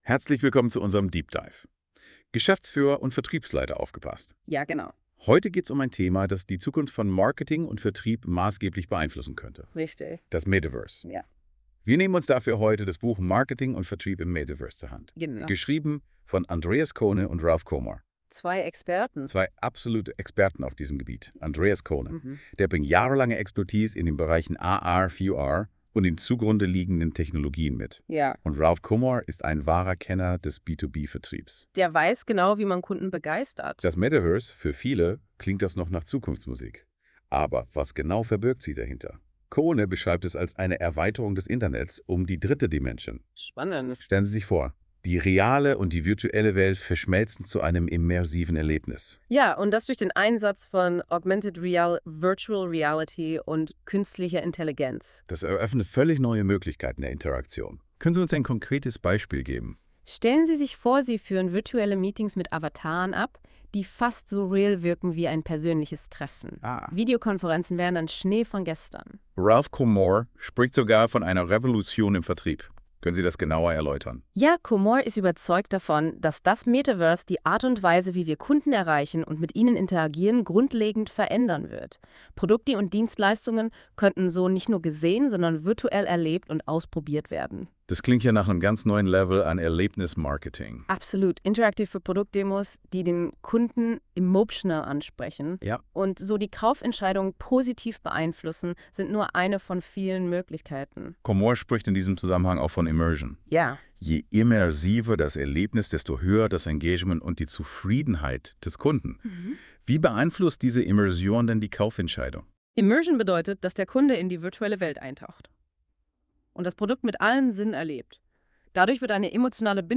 Warum Unternehmen das Metaverse nicht ignorieren dürfen | Interview | Podcast
Der Podcast wurde von künstlicher Intelligenz generiert.
Entschuldigung für das manchmal holprige Deutsch, das Tool ist noch in der Beta-Phase.